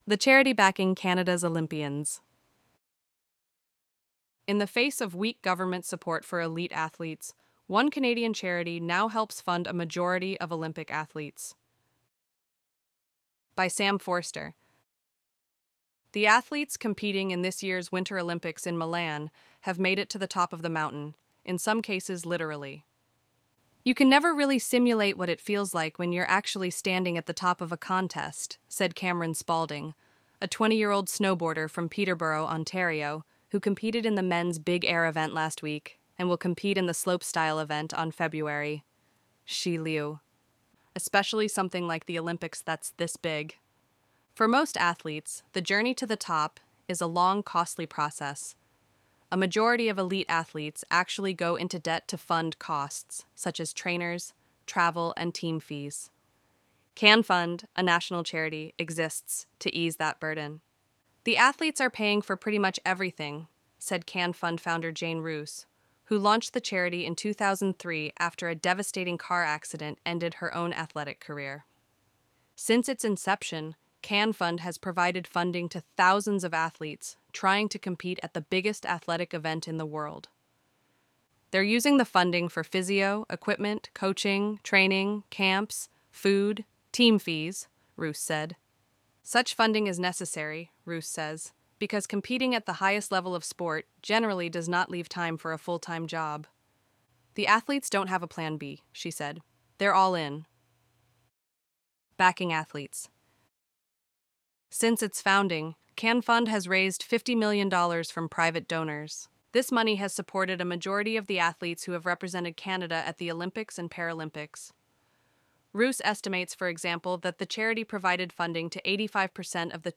ElevenLabs_Untitled_project-20.mp3